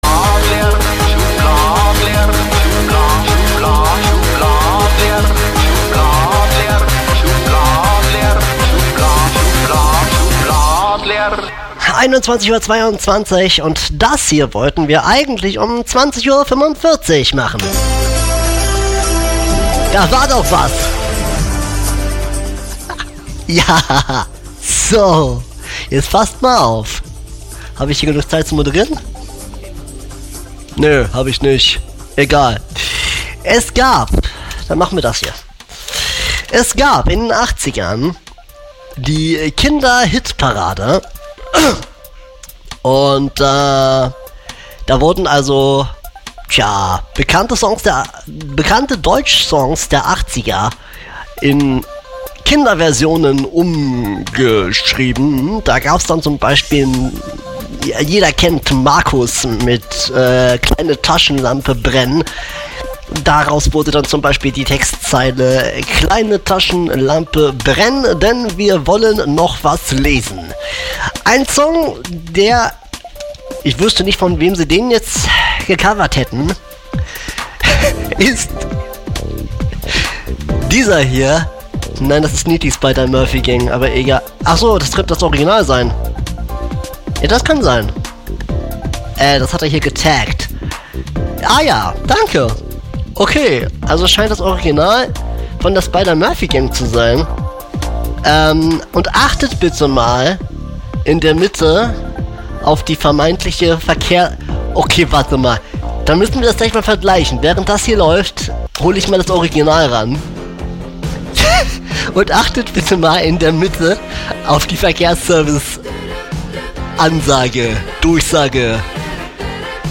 bayern3 verkehrsfunk mit ner bayrischen band.wav